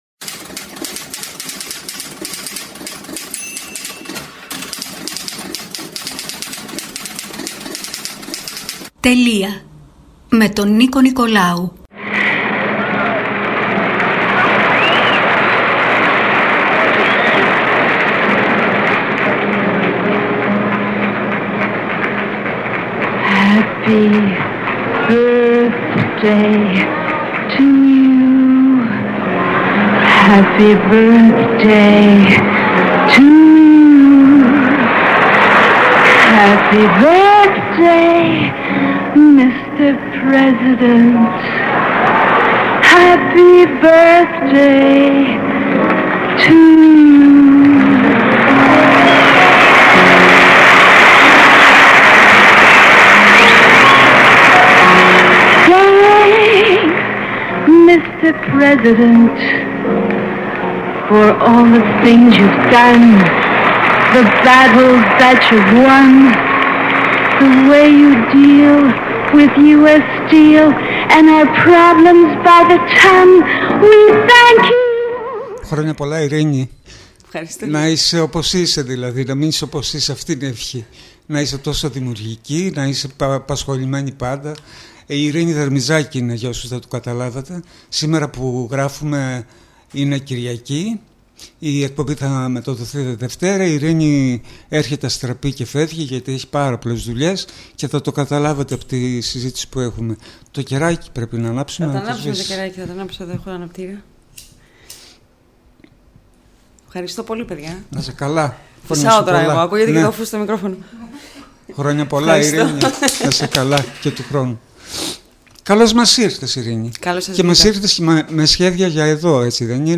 ΑΚΟΥΣΤΕ ΟΛΟΚΛΗΡΗ ΤΗΝ ΣΥΝΕΝΤΕΥΞΗ ΕΔΩ: